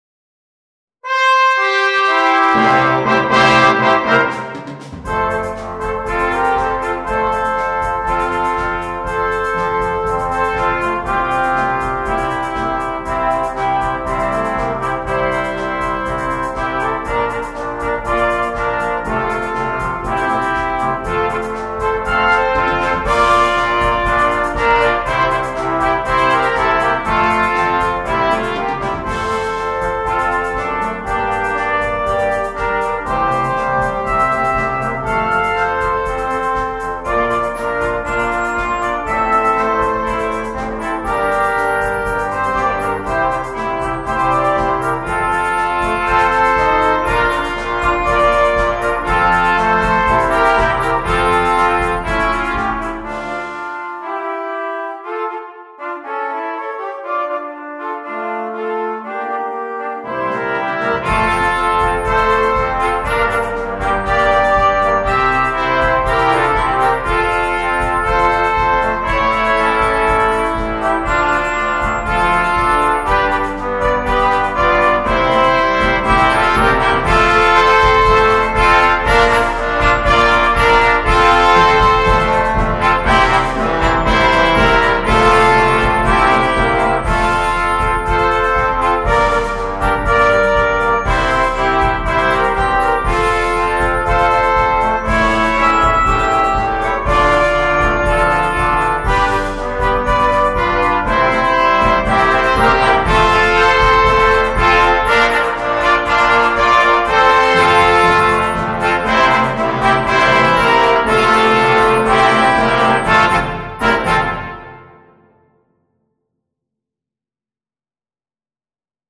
2. Junior Band (flex)
4 Parts & Percussion
without solo instrument
Entertainment
Percussion